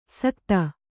♪ sakta